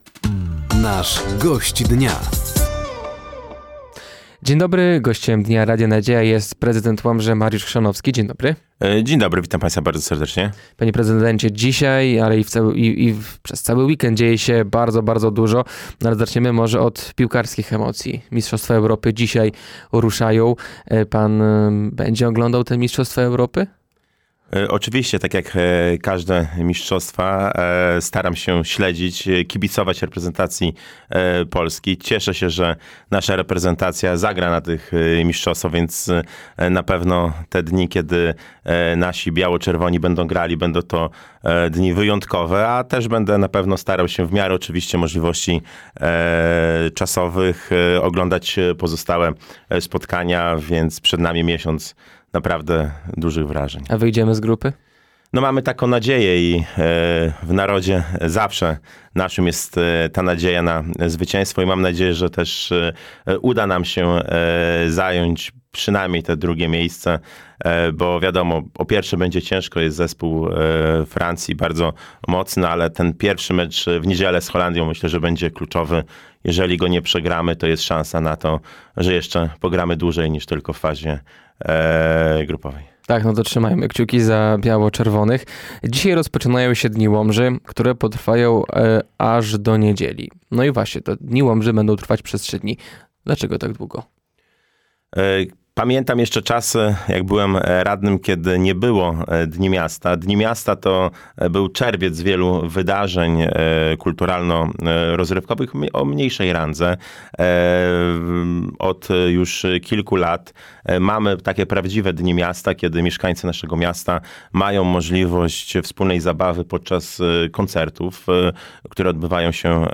Gościem Dnia Radia Nadzieja był prezydent Łomży Mariusz Chrzanowski. Tematem rozmowy były Dni Łomży oraz sesja absolutoryjna.